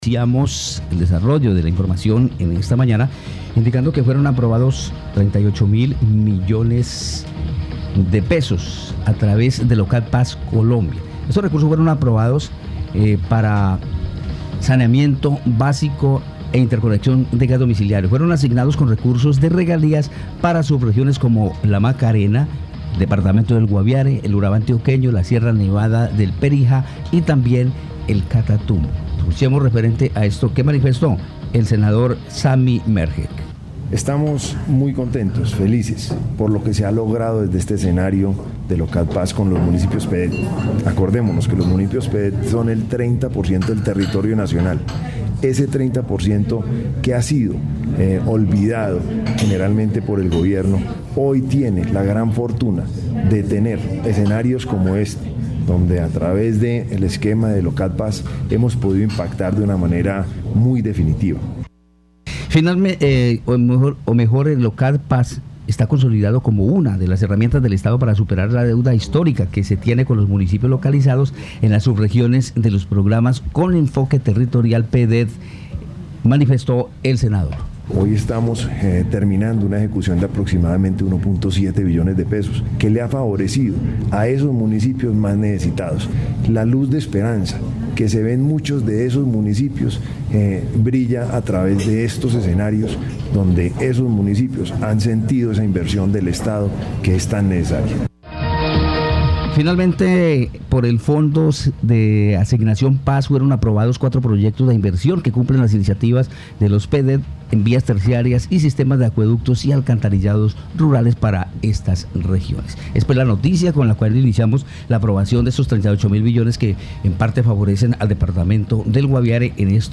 Escuche a Samy Mergeh, senador de la República de Colombia.